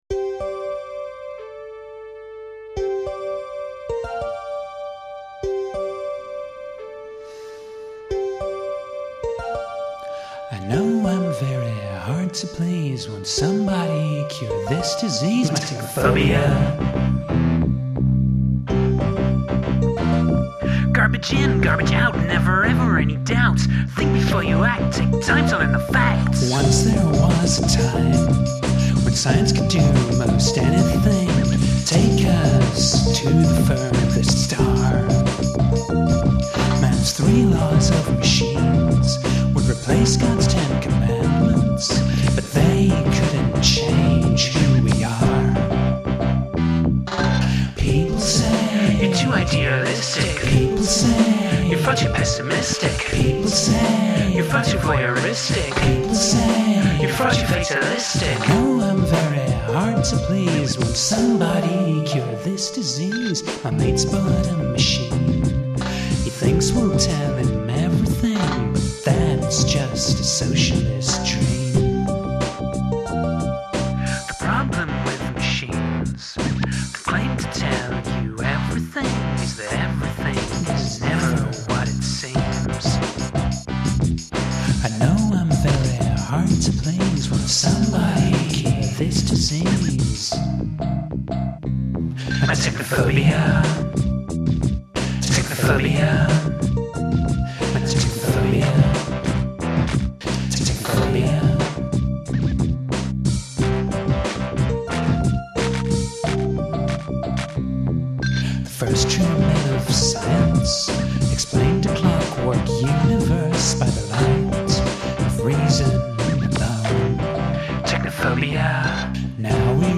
These songs are all 128 Kbit/s stereo MP3s.